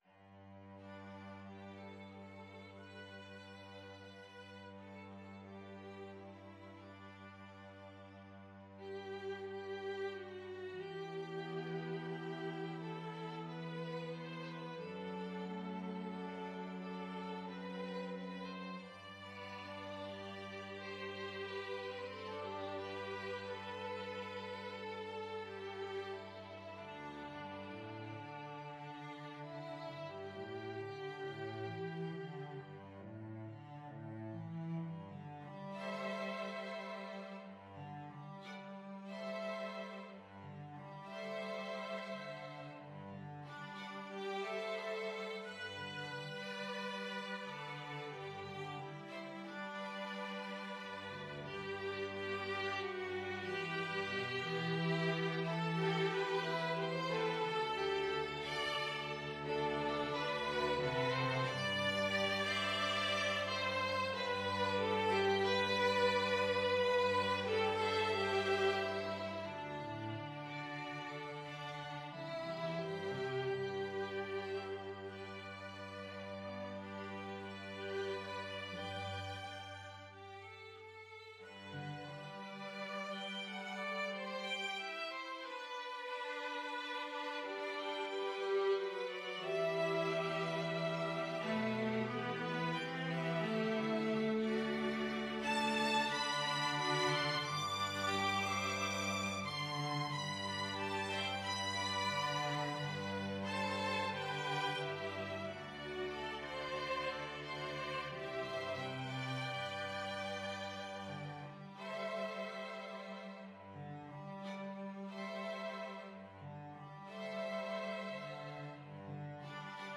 4/4 (View more 4/4 Music)
Andante cantabile = c. 90